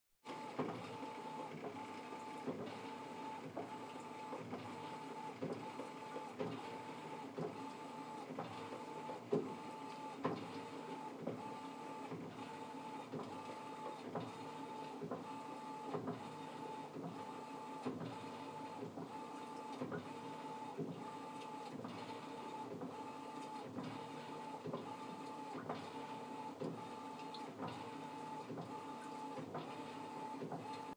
Washing Machine – The Quiet Cycle
washing-machine-the-quiet-cycle.m4a